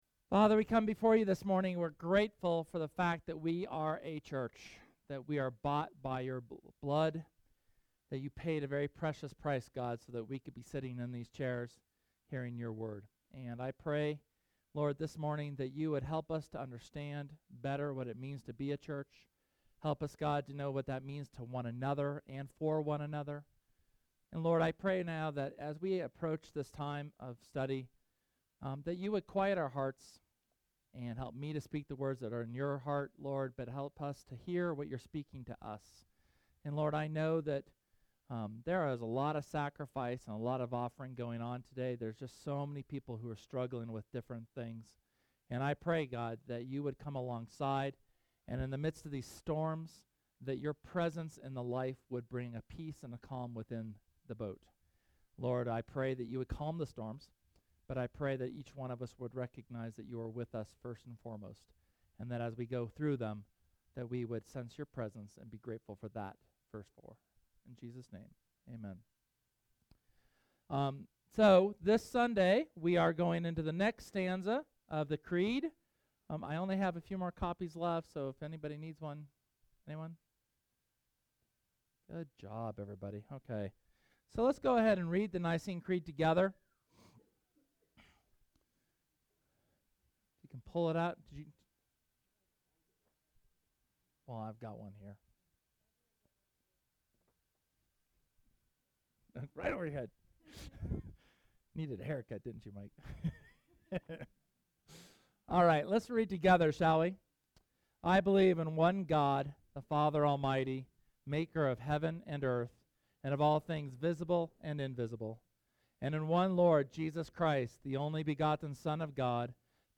The ninth sermon in our series on the Christian Creeds (Apostles, Nicene and Athanasian Creeds). These creeds help us in our understanding of the fundamentals of the faith so that we may be better equipped to minister to others.